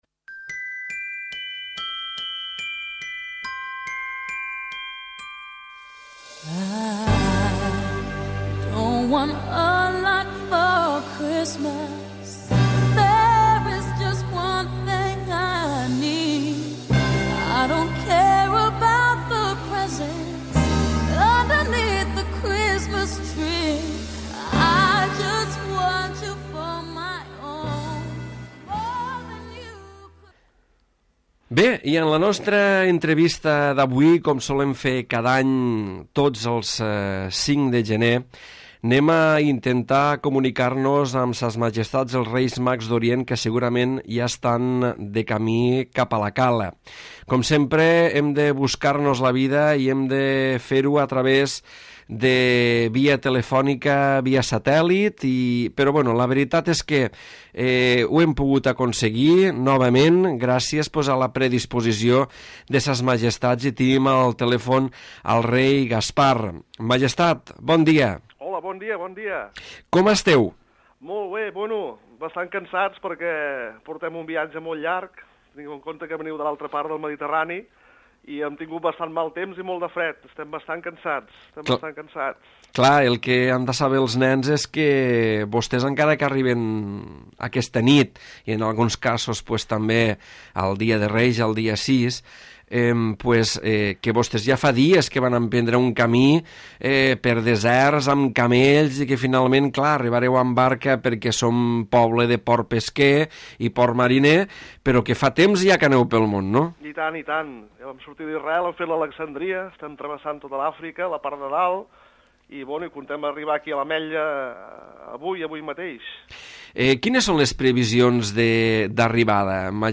L'Entrevista
Entrevista telefònica a SSMM Gaspar.